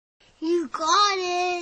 Kategorie SMS